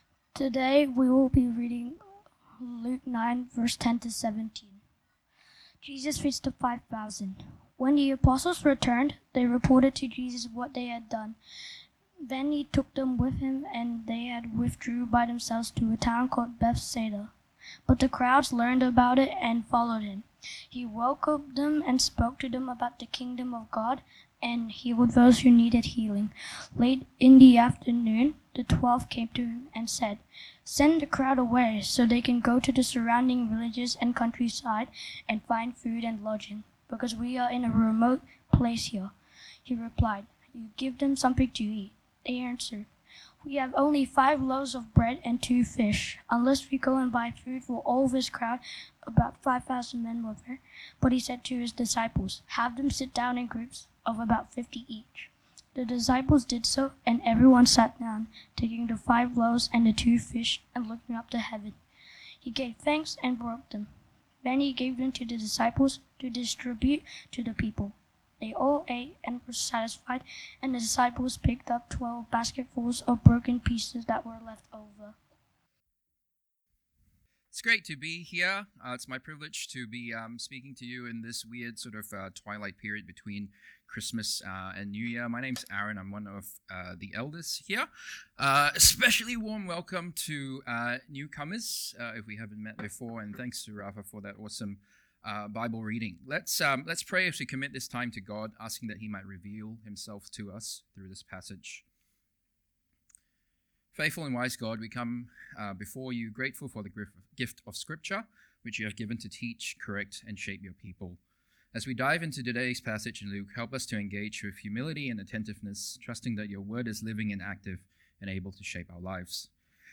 A message from the series "Christmas 2025."